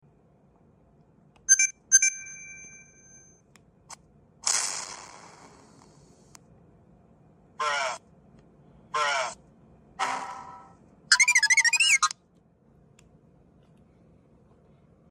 Testing this board out to more easily change xbox 360 boot/eject sounds or to add them to the phat or slim E consoles.